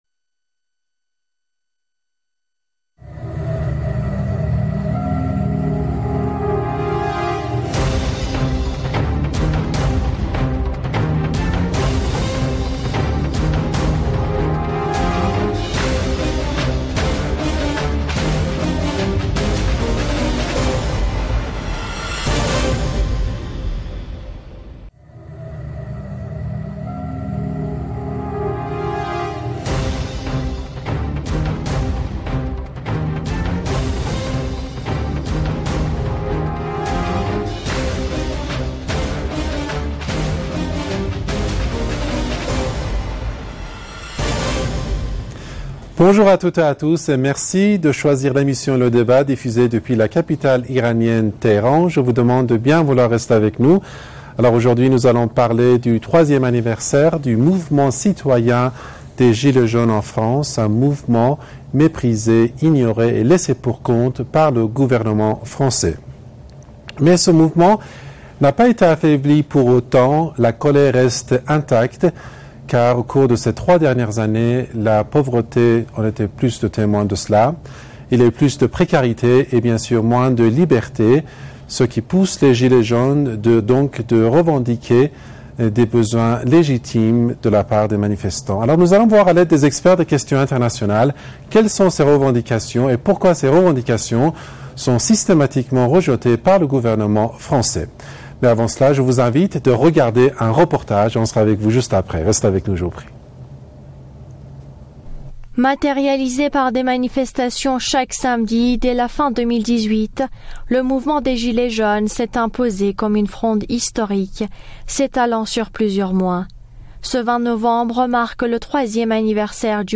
Mots clés International France guerre table ronde Gilets jaunes Eléments connexes Quel rôle jouent les éléments saboteurs et terroristes dans les troubles en Iran ?